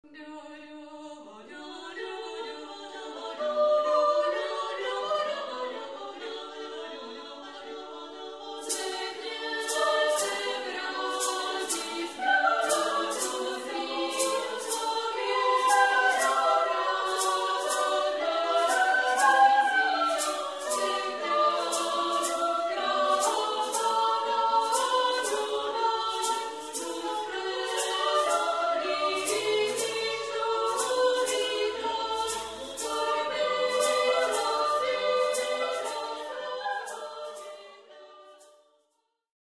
Komorní pěvecké sdružení Ambrosius
Demo nahrávka, prosinec 2000